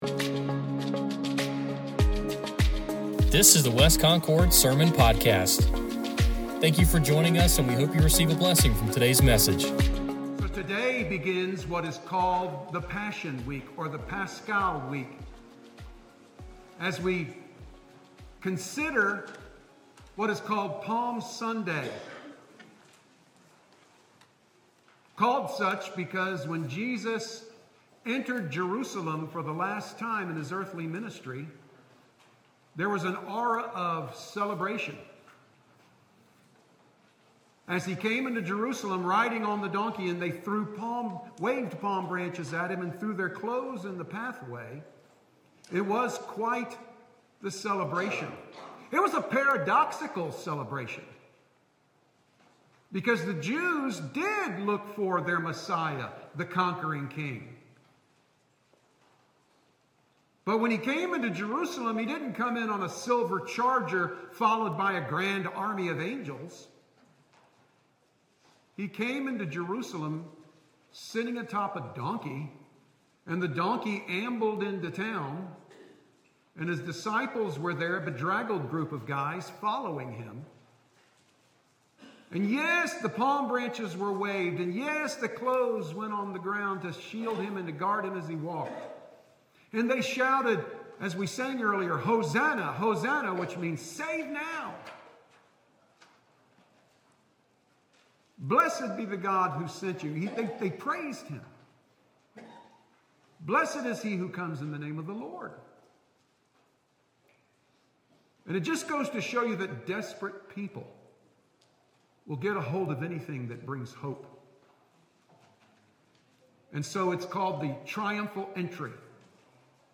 Sermon Podcast | West Concord Baptist Church